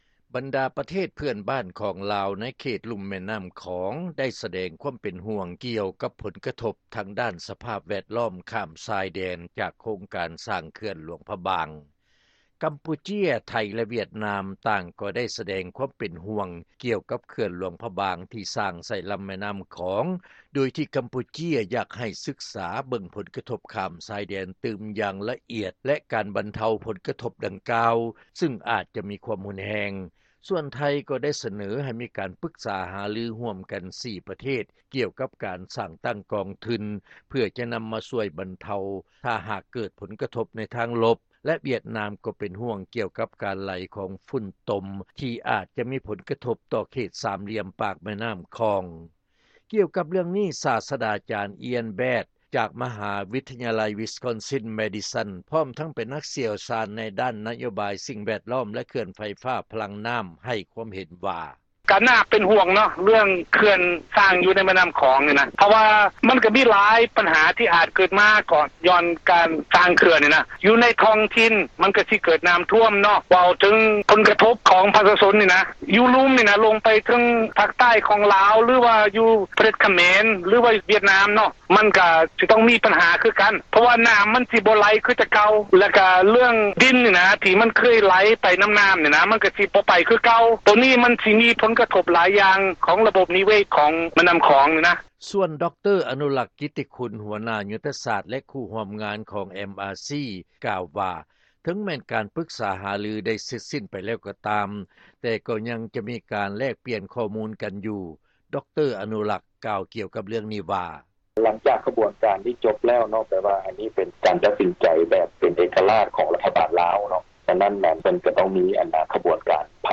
ເຊີນຟັງລາຍງານ ເພື່ອນບ້ານໃນເຂດລຸ່ມແມ່ນ້ຳຂອງ ຍັງສືບຕໍ່ມີຄວາມເປັນຫ່ວງ ກ່ຽວກັບການສ້າງເຂື່ອນຫຼວງພະບາງ